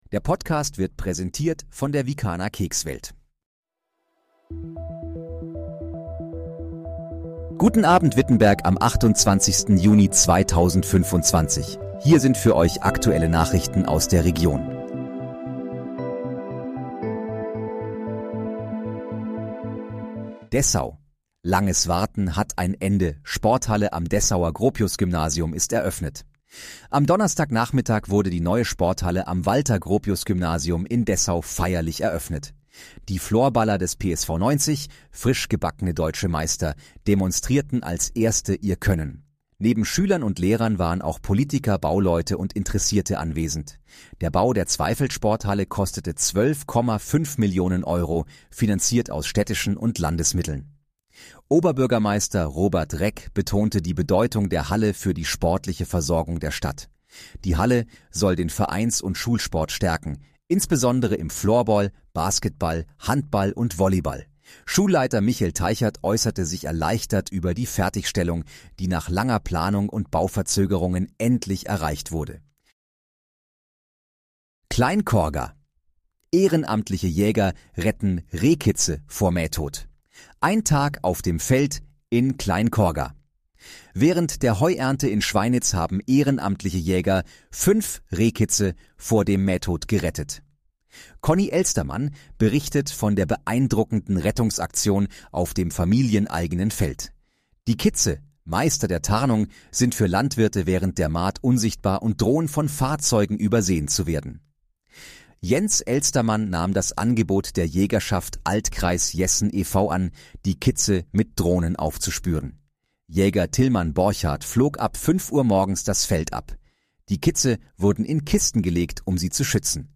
Guten Abend, Wittenberg: Aktuelle Nachrichten vom 27.06.2025, erstellt mit KI-Unterstützung
Nachrichten